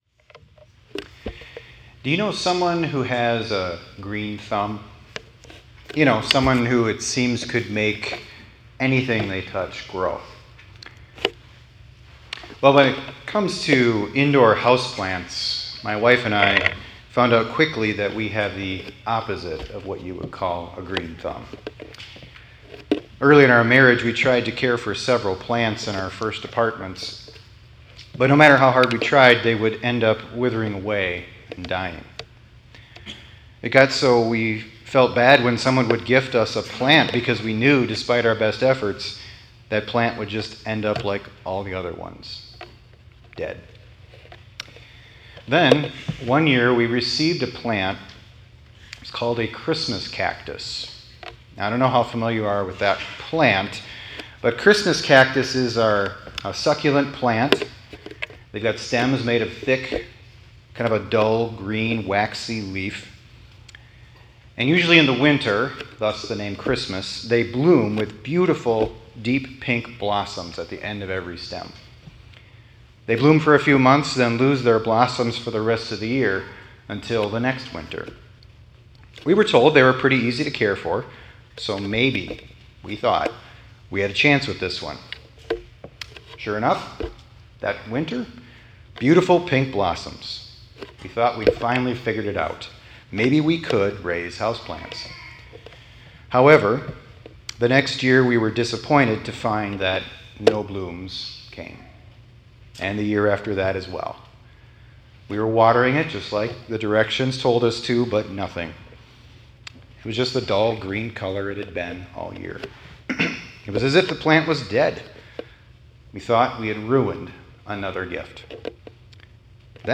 2025-04-25 ILC Chapel — This Mortal Must Put On Immortality